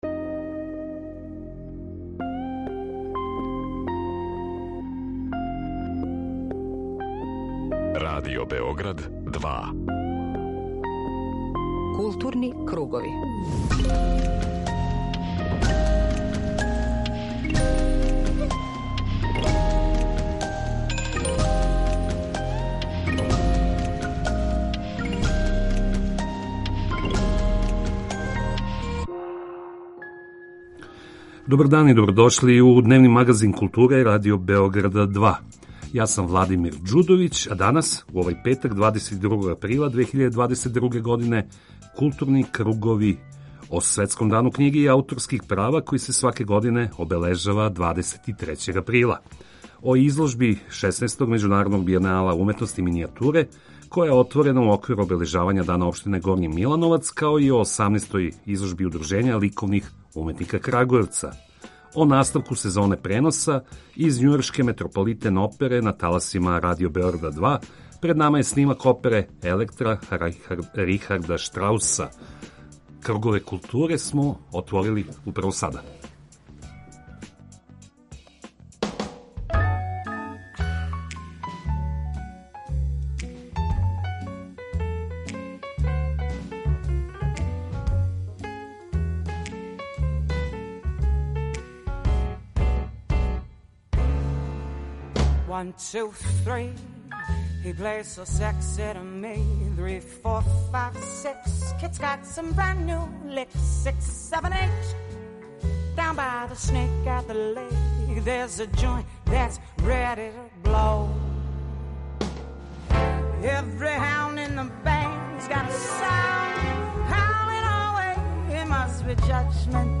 У сусрет Светском дану књиге разговарамо са писцима и издавачима, али и испитујемо наше поимање књиге у њеним различитим појавним облицима. Из области визуелних уметности издвајамо изложбу 16. Међународног бијенала уметности минијатуре, која је отворена у оквиру обележавања Дана општине Горњи Милановац, као и 18. Пролећну изложбу Удружења ликовних уметника Крагујевца. Најавићемо и наставак сезоне преноса из њујоршке Метрополитен опере на таласима Радио Београда 2.